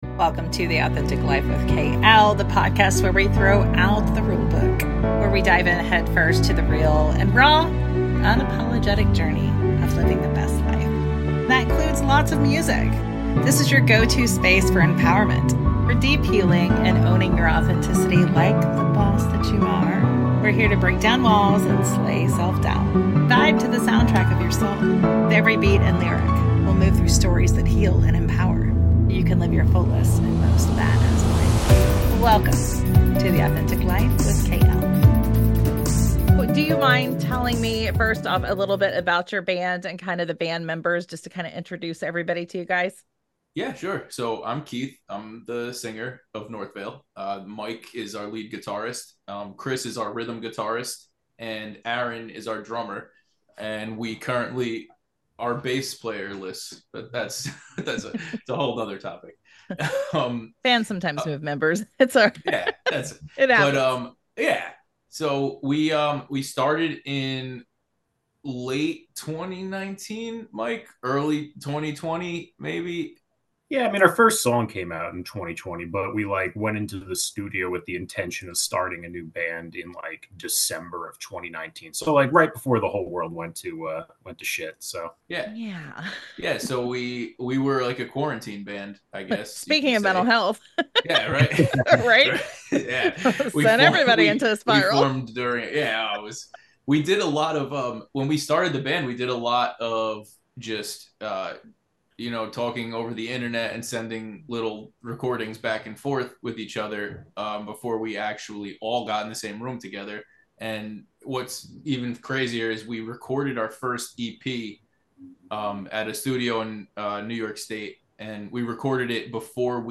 Today, I was honored to sit down with the talented band Northvale, who have truly outdone themselves this year with not one, not two, but three incredible new releases.